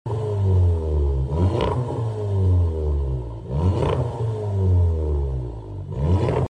Vw Golf 7 GTI came in for a non res Cat back exhaust.